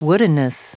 Prononciation du mot woodenness en anglais (fichier audio)
Prononciation du mot : woodenness